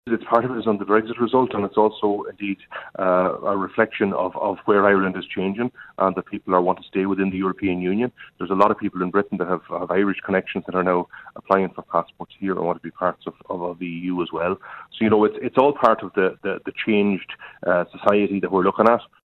Deputy Martin Kenny says it’s clear many people want to remain part of the European Union…………